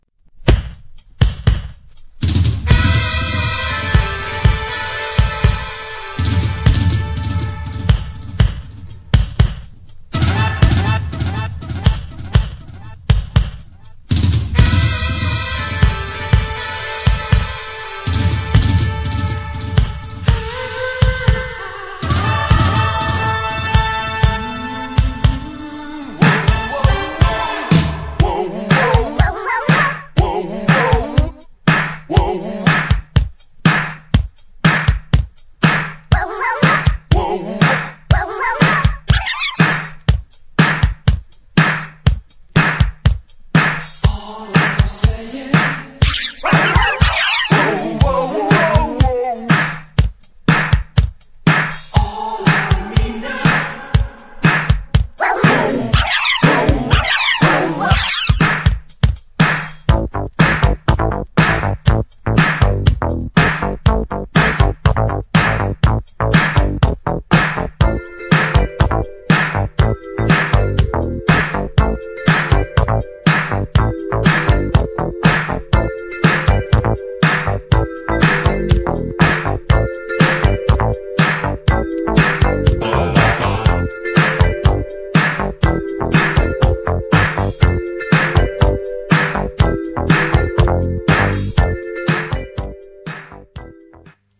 US REMIXはかなりダビーでイケます!! これもGARAGE CLASSIC!!
GENRE Dance Classic
BPM 121〜125BPM